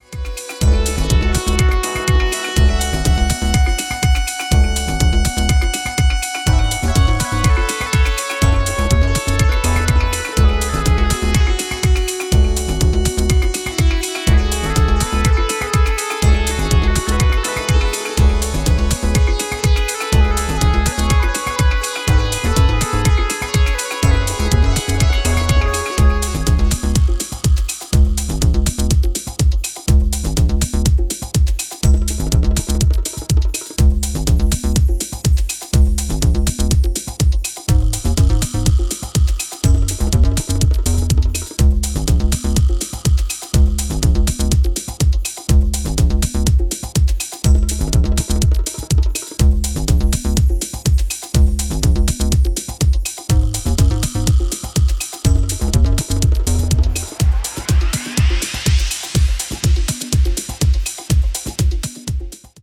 instrumental remix